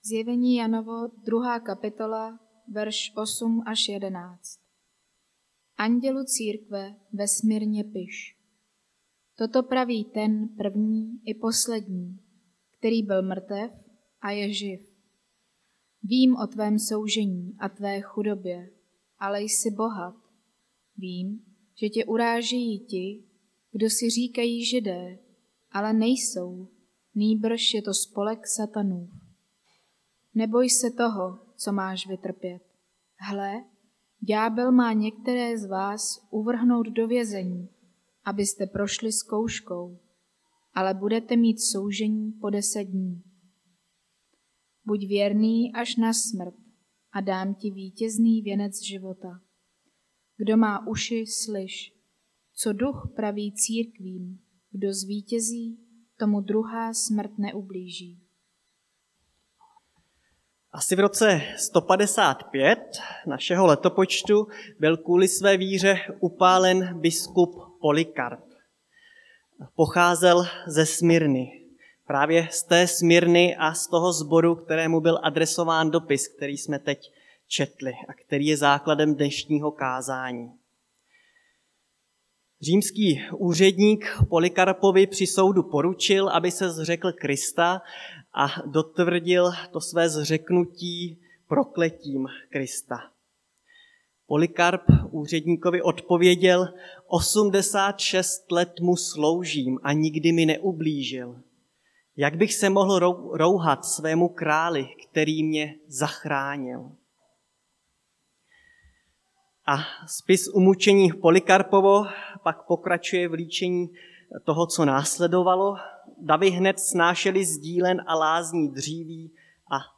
Nedělení kázání – 8.5.2022 List do Smyrny